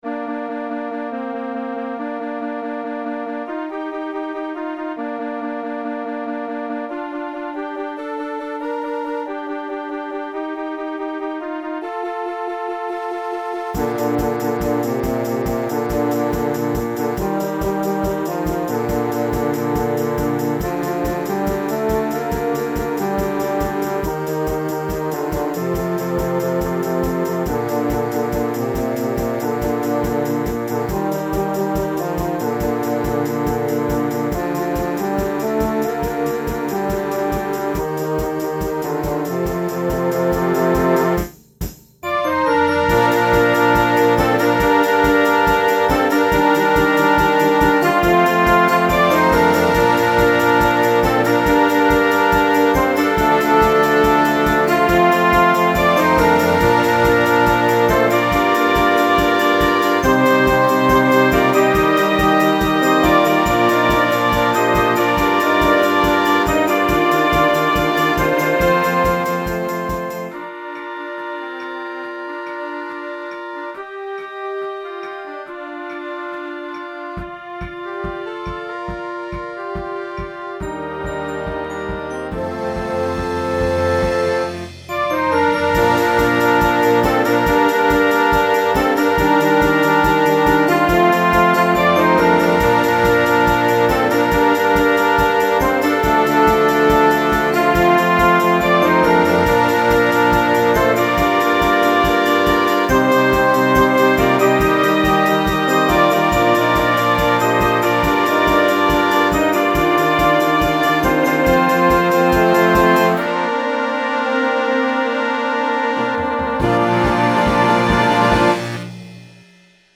pop, rock, children, film/tv, movies, instructional